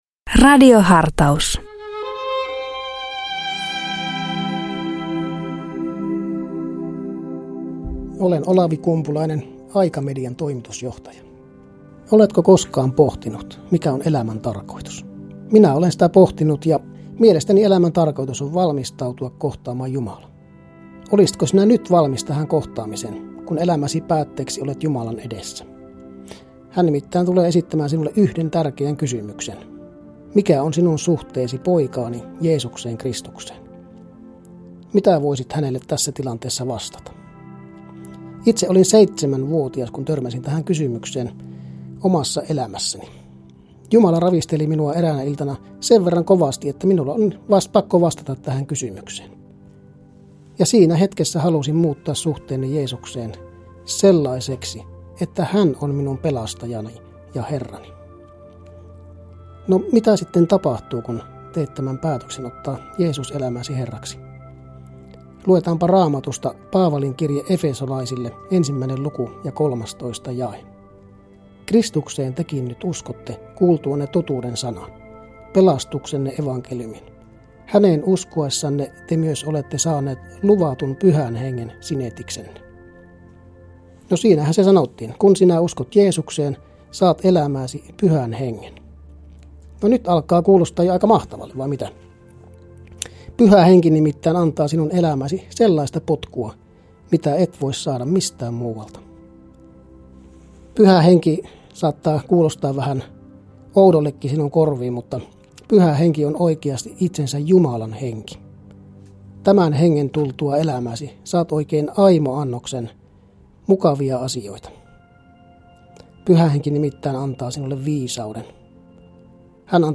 Radio Dei lähettää FM-taajuuksillaan radiohartauden joka arkiaamu kello 7.50. Radiohartaus kuullaan uusintana iltapäivällä kello 16.50. Radio Dein radiohartauksien pitäjinä kuullaan laajaa kirjoa kirkon työntekijöitä sekä maallikoita, jotka tuntevat radioilmaisun omakseen.